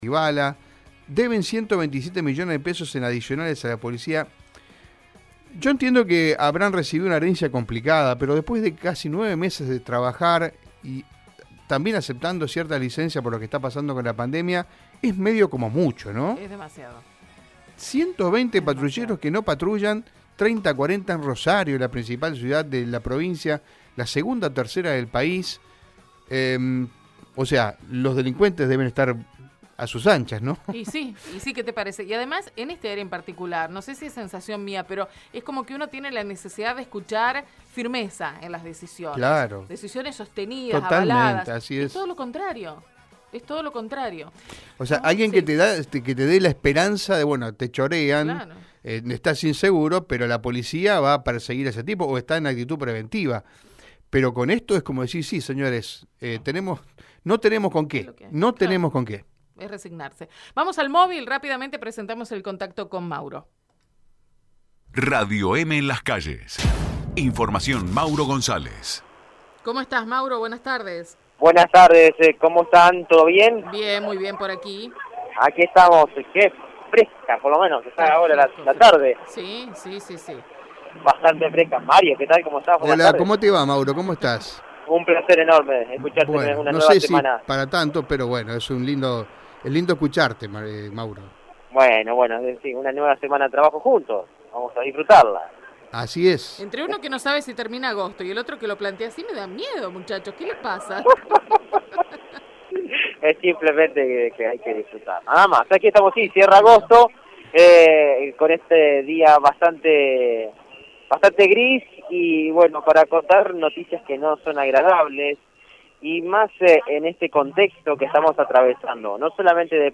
En tanto, en dialogo con el móvil de Radio EME, una de las empleadas del efector de salud indicó: «Somos 10 trabajadores y queremos tener precisiones sobre las indemnizaciones».